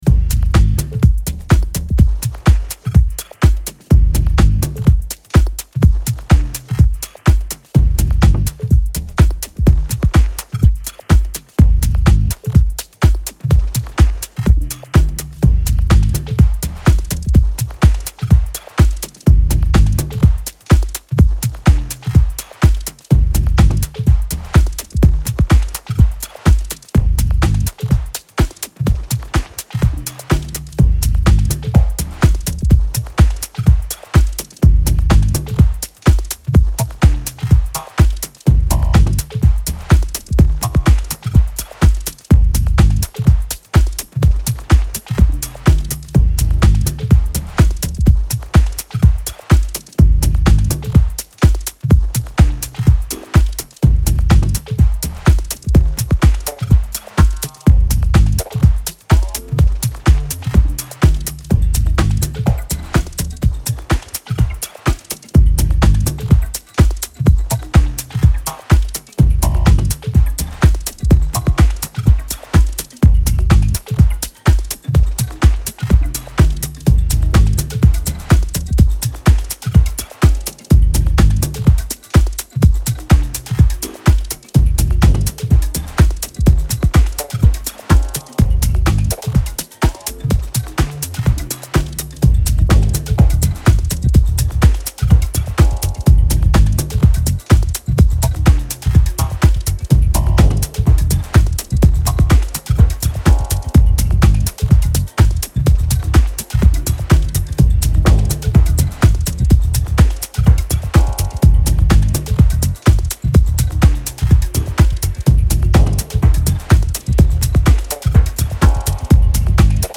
ここでは、繊細な音響処理が施されたダークでクールなミニマル・ハウスを全4曲展開。